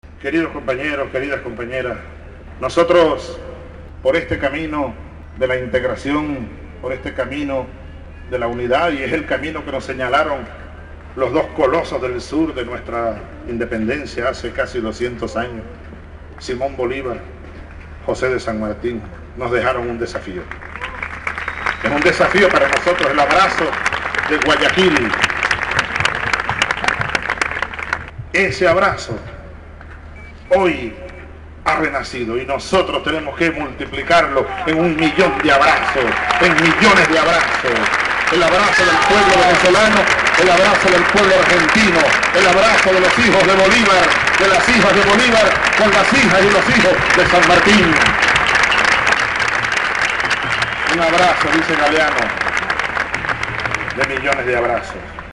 Señaló el presidente venezolano Hugo Chavez Frías al recibir, en el marco de un acto multitudinario, el «Premio Rodolfo Walsh» por su compromiso como «Presidente Latinoamericano por la Comunicación Popular»
El acto tuvo lugar en el predio de la Facultad, donde se levantó un escenario para la ocasión, al que concurrieron personalidades del ámbito local, funcionarios de naciones latinoamericanas y una multitud de jóvenes que le plasmó a la noche un clima de emoción y alegría.